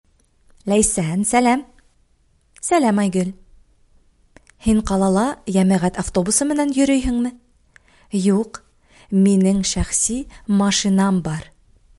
Диалог 1